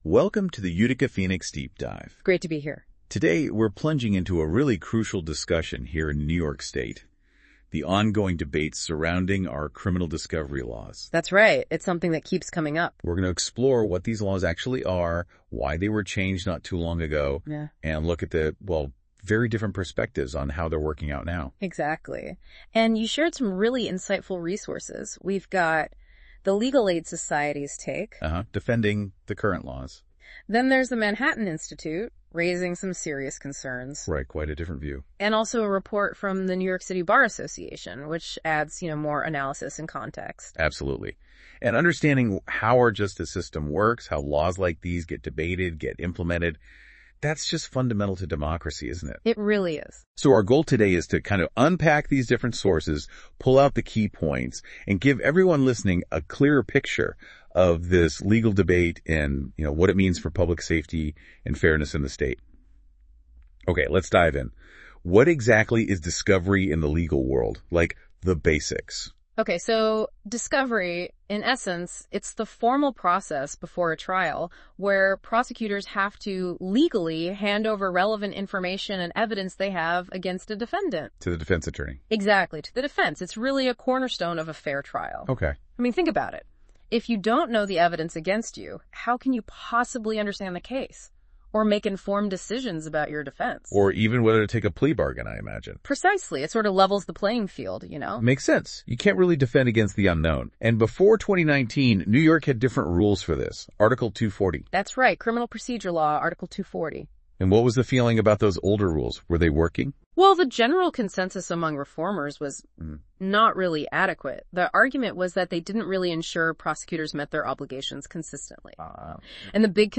Listen to a critical public debate on New York’s discovery law reform and its impact on the state’s justice system.
This audio recording captures a vital public discussion on the issue—highlighting both the concerns over prosecutorial burdens and the outcry for greater transparency and fairness for the accused.